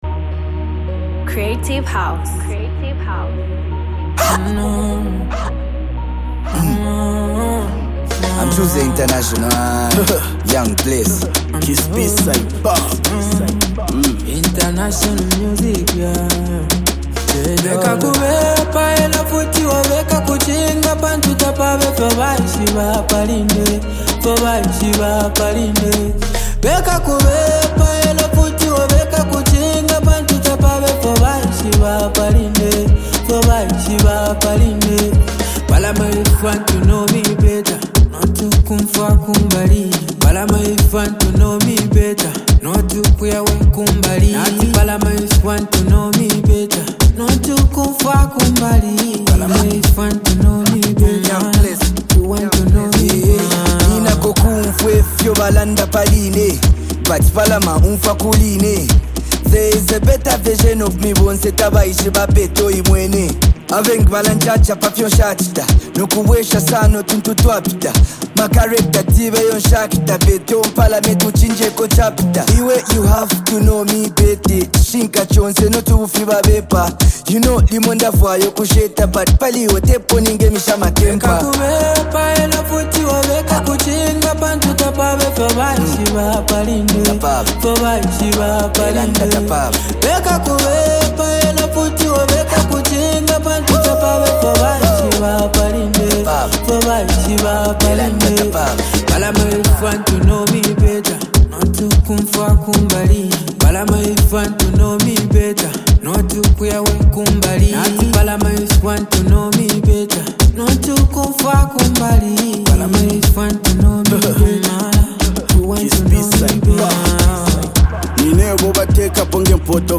seasoned rap delivery